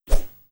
Woosh 01.wav